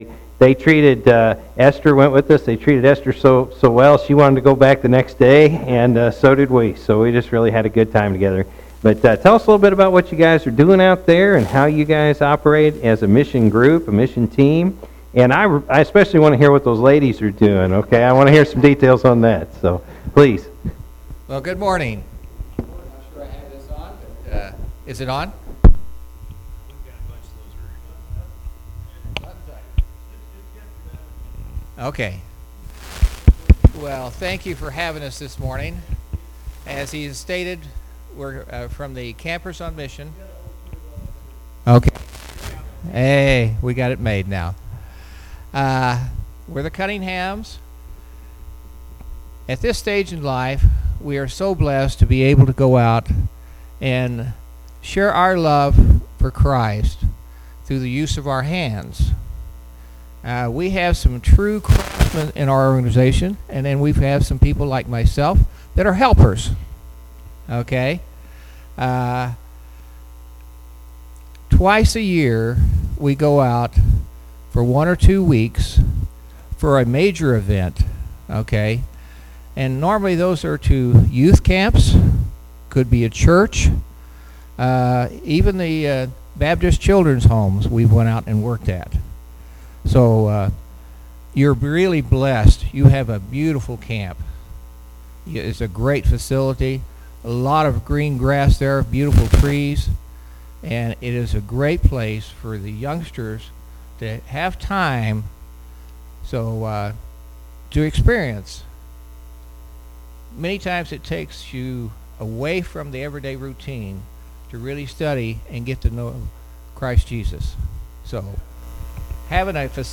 May-7-2017-morning-service.mp3